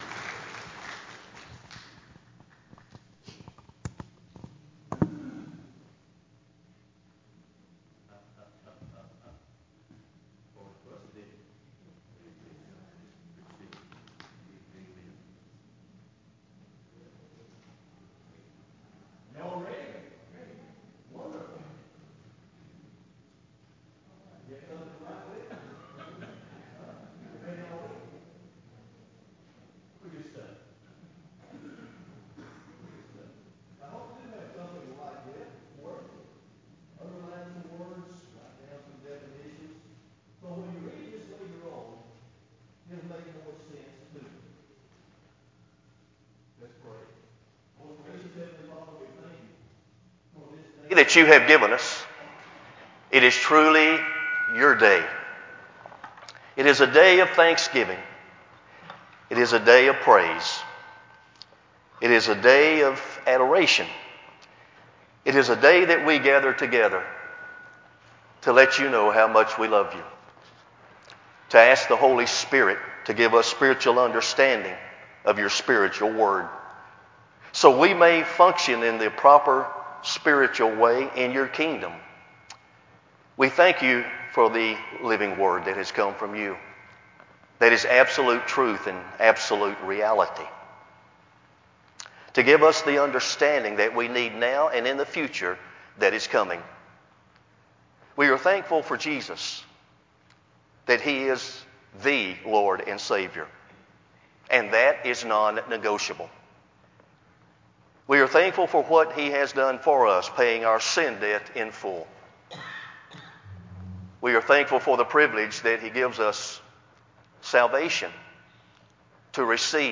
Sermon-Feb-17-CD.mp3